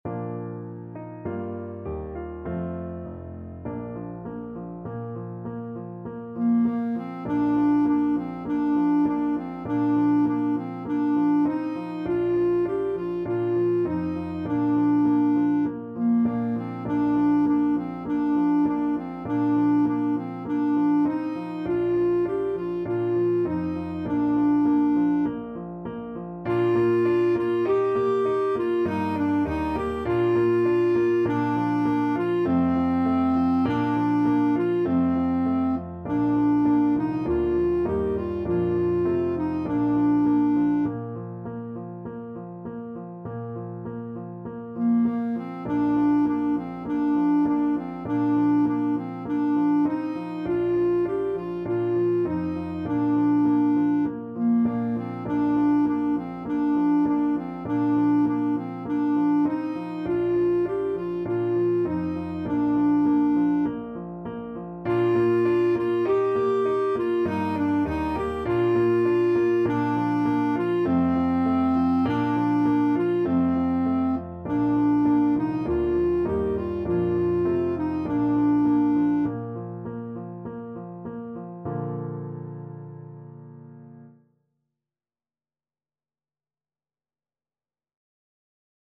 Christmas Christmas Clarinet Sheet Music Es ist fur uns eine Zeit angekommen
4/4 (View more 4/4 Music)
Bb major (Sounding Pitch) C major (Clarinet in Bb) (View more Bb major Music for Clarinet )
Moderato
Clarinet  (View more Easy Clarinet Music)
Traditional (View more Traditional Clarinet Music)